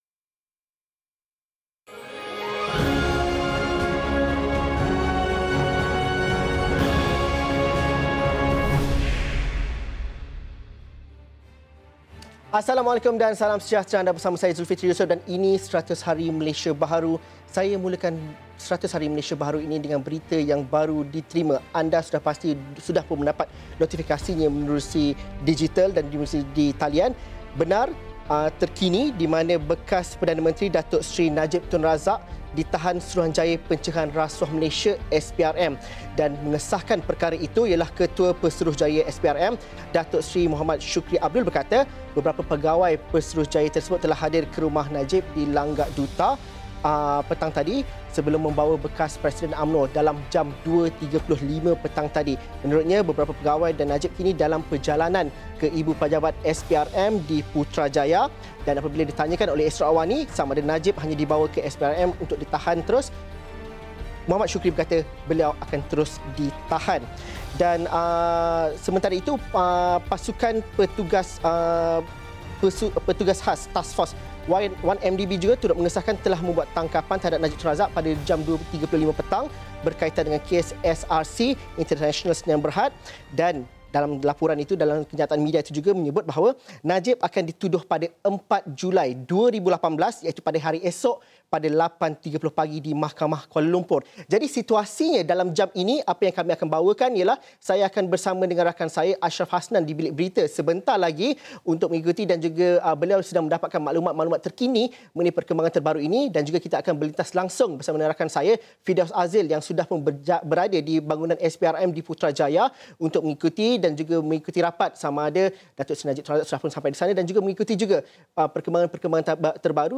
Ikuti laporan Wartawan Astro AWANI
berada di Pejabat SPRM, Putrajaya